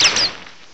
Add all new cries